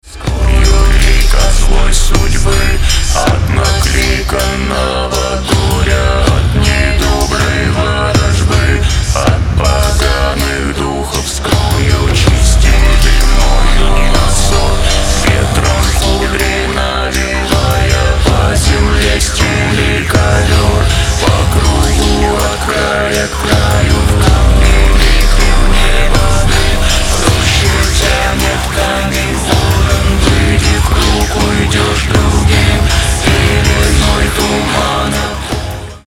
Мистическая тёмная фолк музыка